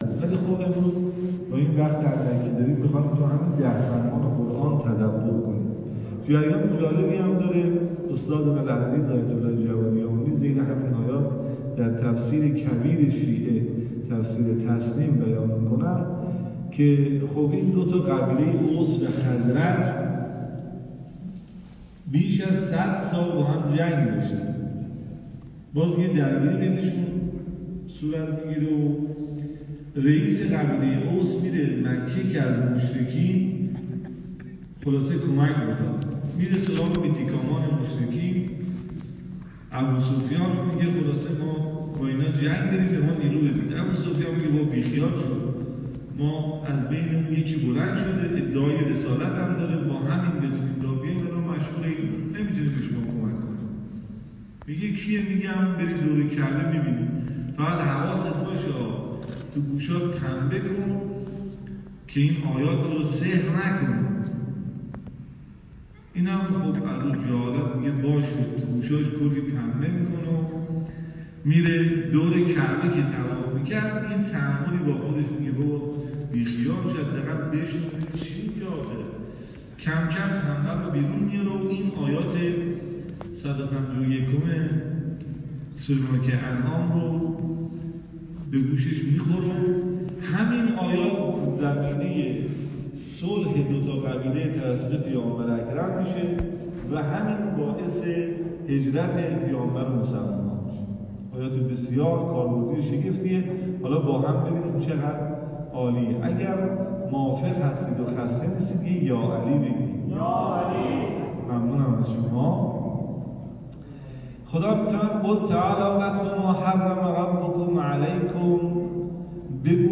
کرسی تلاوت ، مسجد دانشگاه تهران